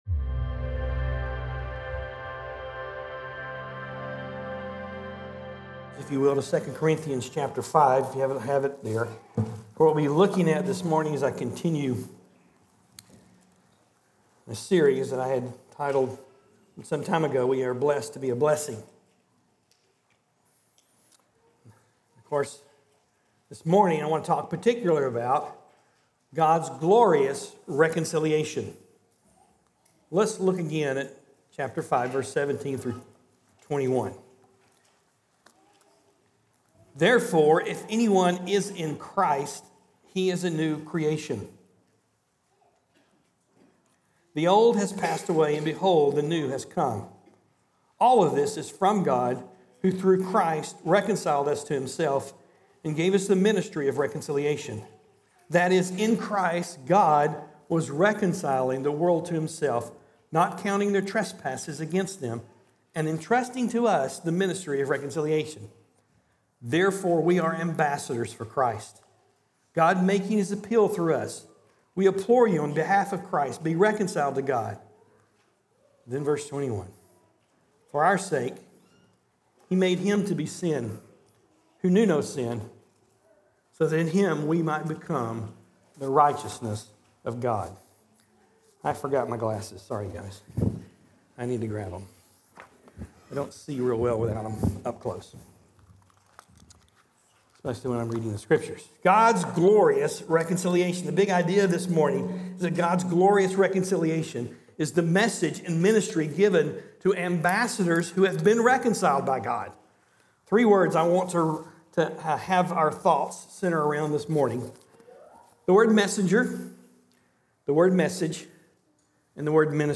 Sermons recorded during the Sunday morning service at Corydon Baptist Church in Corydon, Indiana